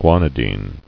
[gua·ni·dine]